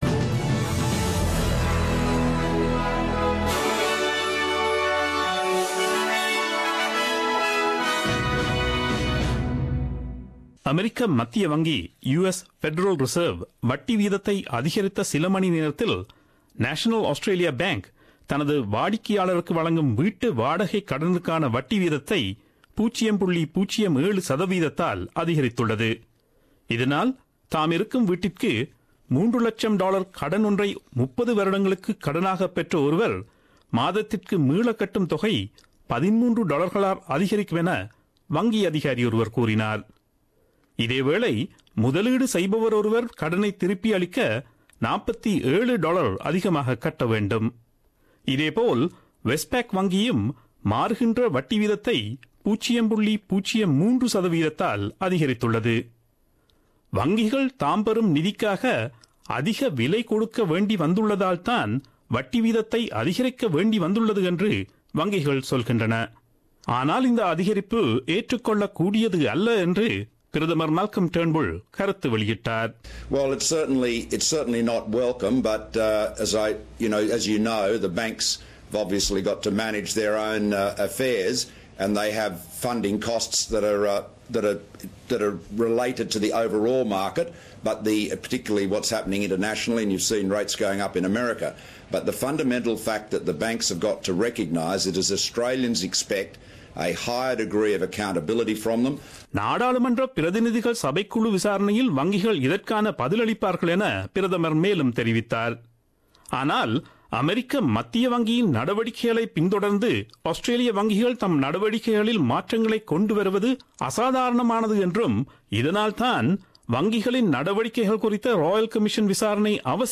Australian news bulletin aired on Friday 17 Mar 2017 at 8pm.